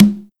BONGO-CONGA9.wav